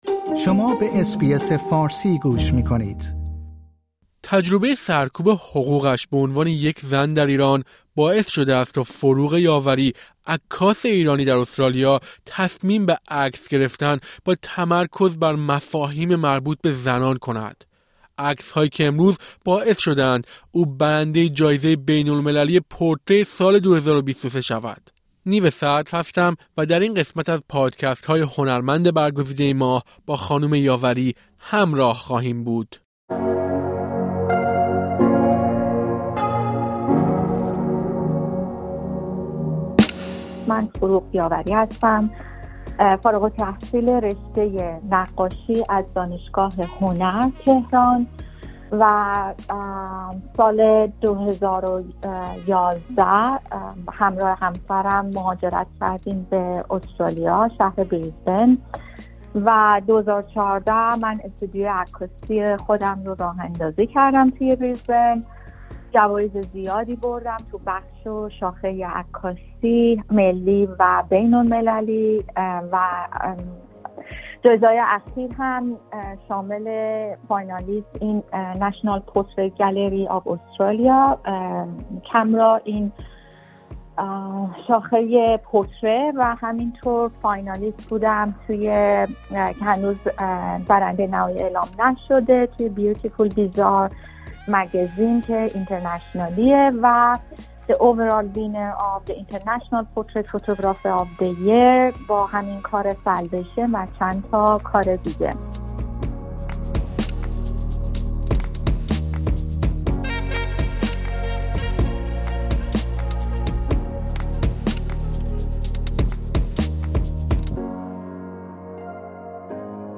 او در گفت‌وگویی با اس‌بی‌اس فارسی درباره تجربه کاری خود توضیح می‌دهد.